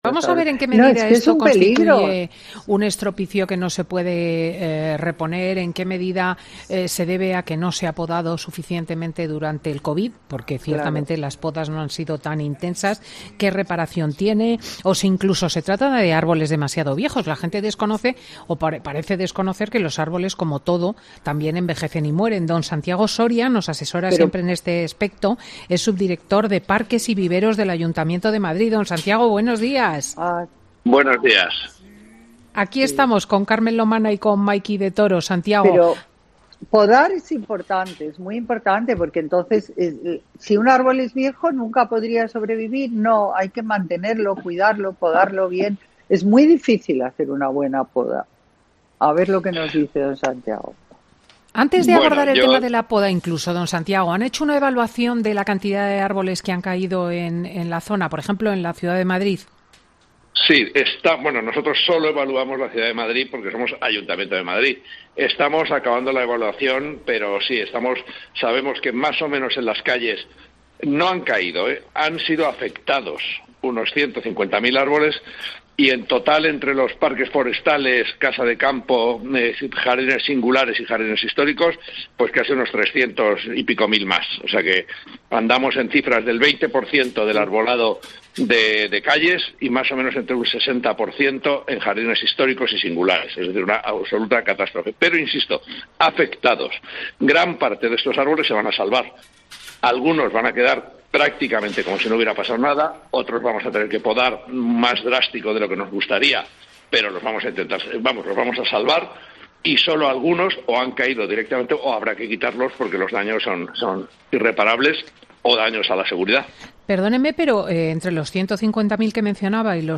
"Fin de Semana" es un programa presentado por Cristina López Schlichting, prestigiosa comunicadora de radio y articulista en prensa, es un magazine que se emite en COPE, los sábados y domingos, de 10.00 a 14.00 horas.